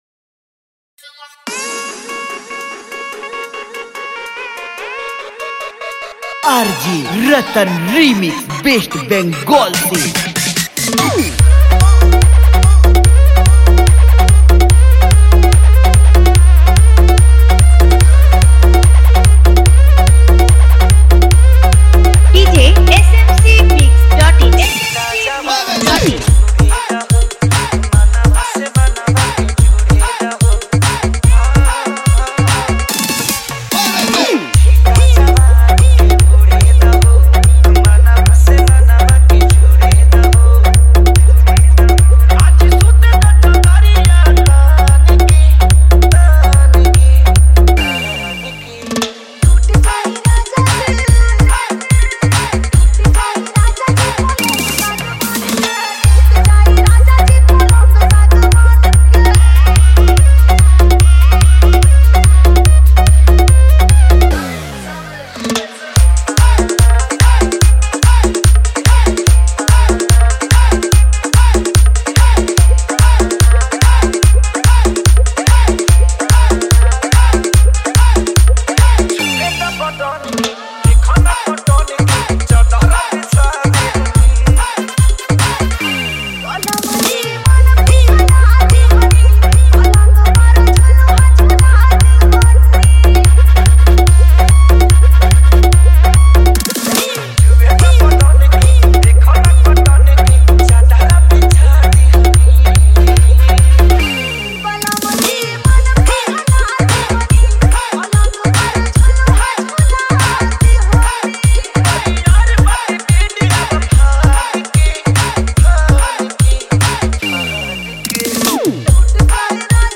Contai No Voice Tag Dj Download Site .....